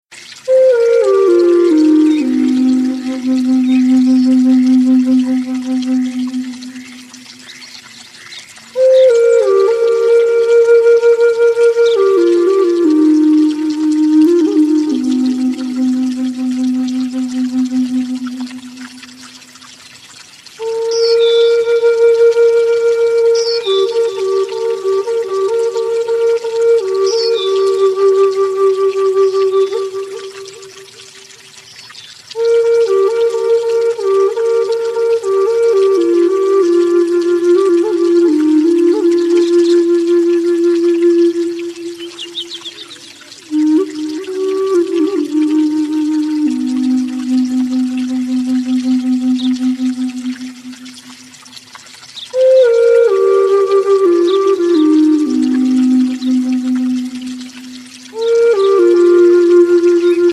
Native American Flute & Folk Music